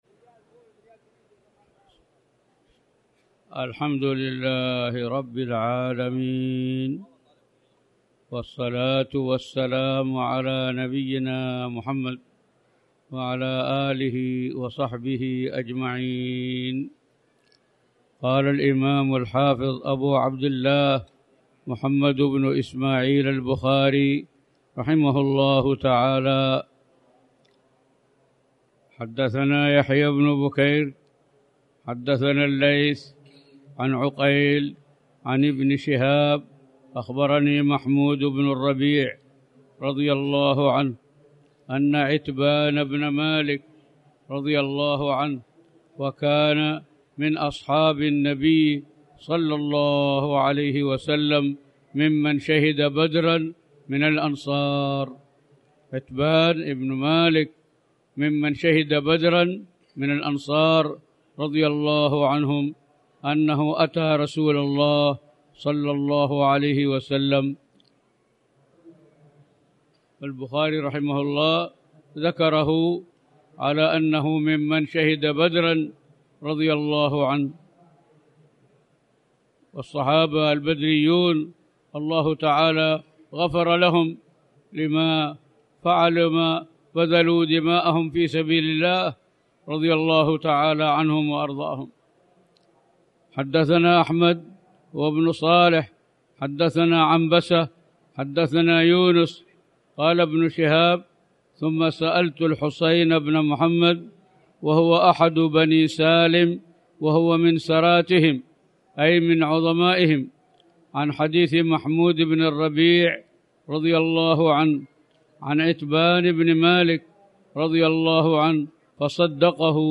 تاريخ النشر ٢٥ رجب ١٤٣٩ هـ المكان: المسجد الحرام الشيخ